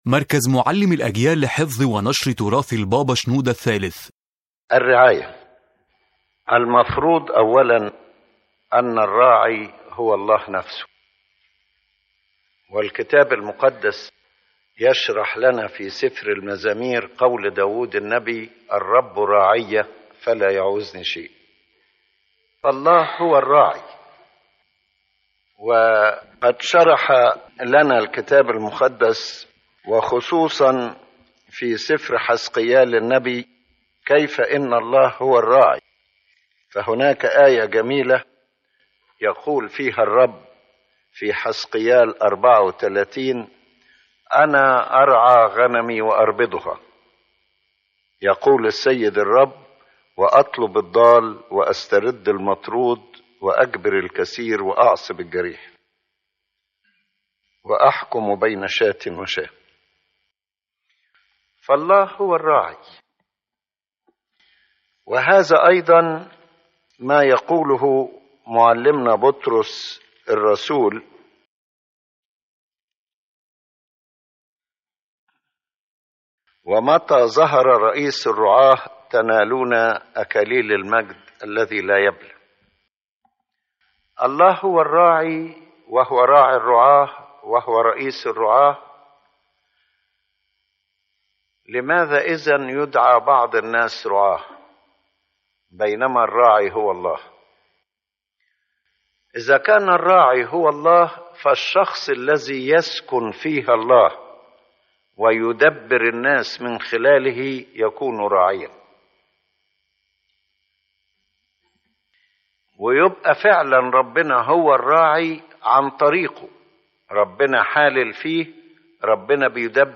⬇ تحميل المحاضرة الفكرة الأساسية للمحاضرة توضح المحاضرة أن الراعي الحقيقي هو الله نفسه، كما أعلن الكتاب المقدس، وأن كل من يُدعى راعيًا إنما هو وكيل يعمل الله من خلاله.